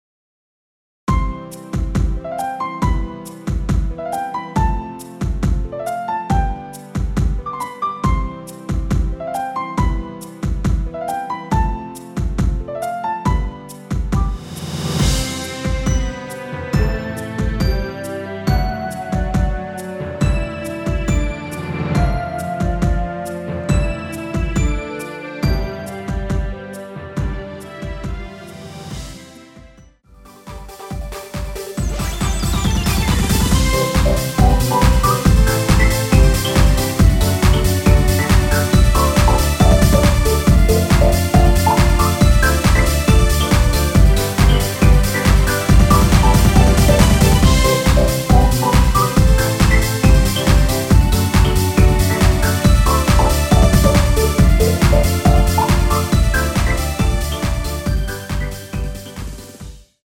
남성분이 부르실 수 있는 키의 MR입니다.(미리듣기 확인)
원키에서(-7)내린 멜로디 포함된 MR입니다.
앞부분30초, 뒷부분30초씩 편집해서 올려 드리고 있습니다.